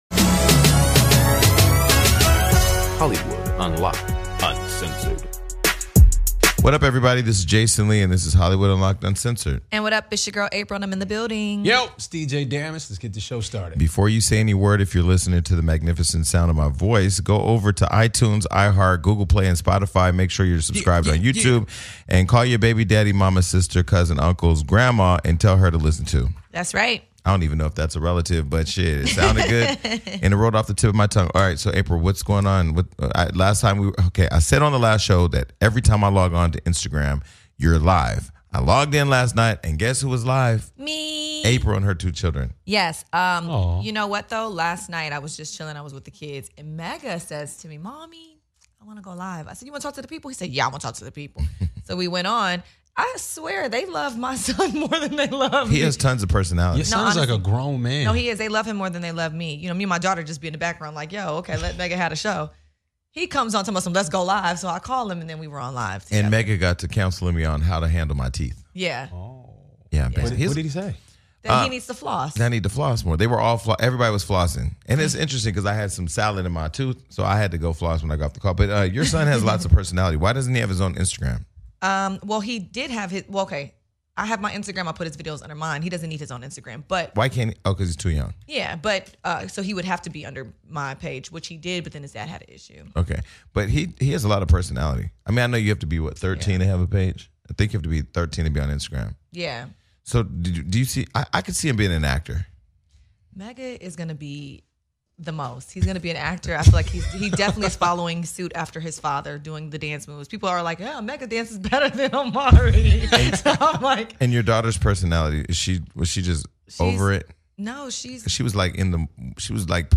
Today our UNCENSORED hosts are joined by the one and only DeVon Franklin!